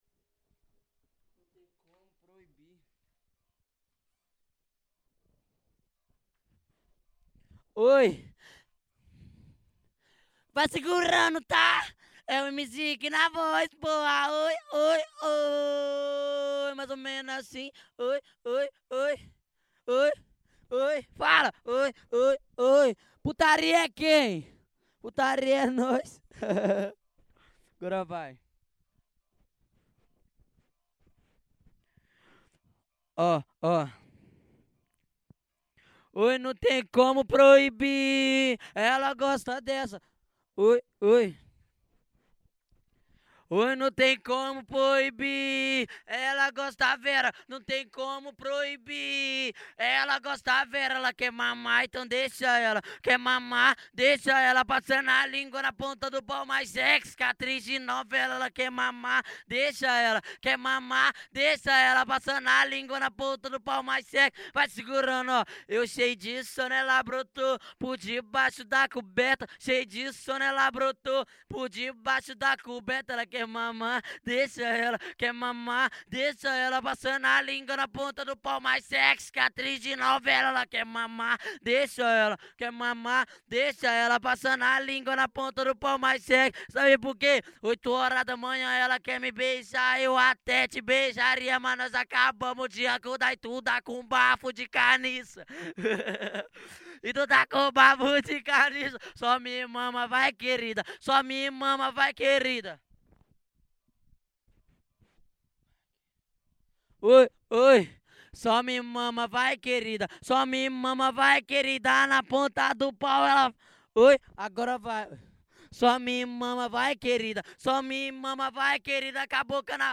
Acapella de Funk